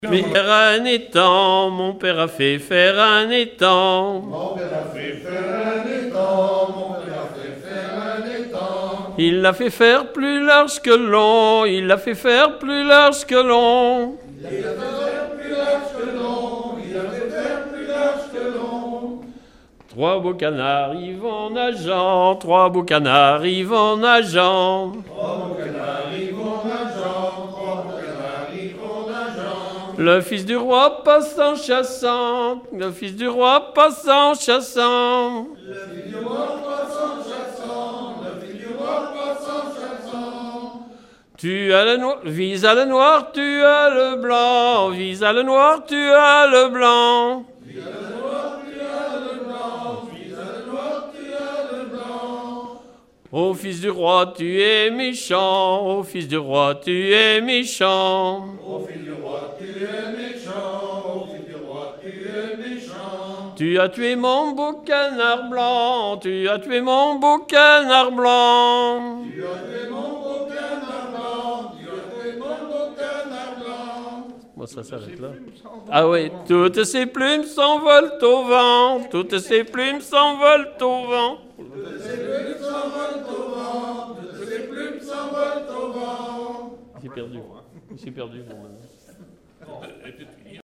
enregistrement de chansons
Pièce musicale inédite